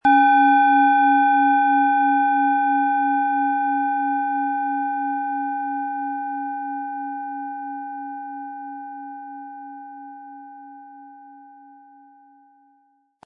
Planetenton
Von Hand getriebene Klangschale mit dem Planetenklang Pluto aus einer kleinen traditionellen Manufaktur.
Im Lieferumfang enthalten ist ein Schlägel, der die Schale wohlklingend und harmonisch zum Klingen und Schwingen bringt.
MaterialBronze